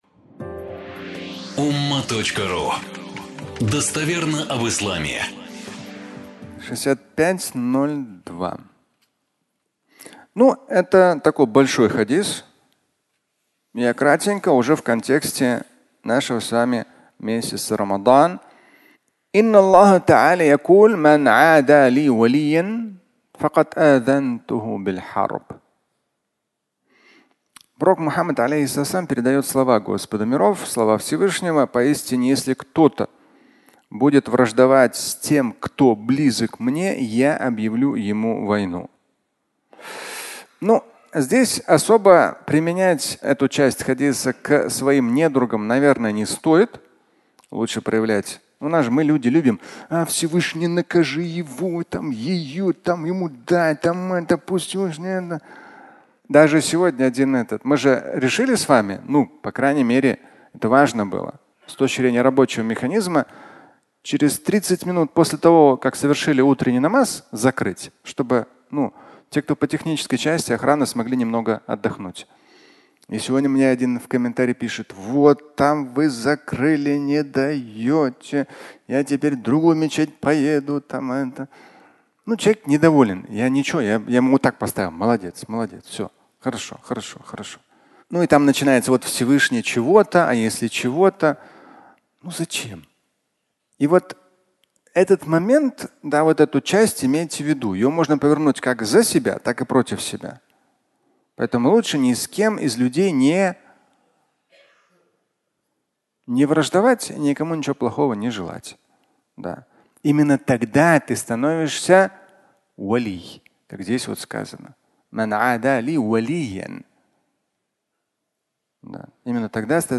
Пост приближает (аудиолекция)